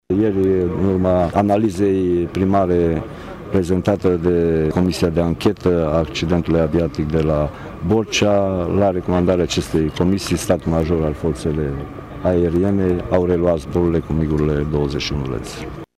Mircea Dușa a mai declarat că ieri s-a ridicat interdicţia pentru aeronavele MiG 21- LanceR, care nu au mai putut decola după accidentul de la Borcea:
Secretarul de stat Mircea Dușa a participat azi, la Tg.Mureș, la ceremonia militară de absolvire a ”Cursului întrunit de calificare operator forțe pentru operații speciale”.